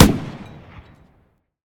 tank-mg-shot-5.ogg